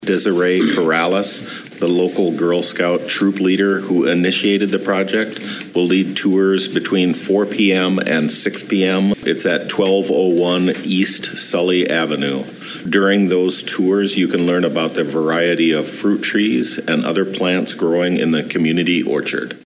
City commissioner Todd Johnson says guided tours will be offered from 4-6pm.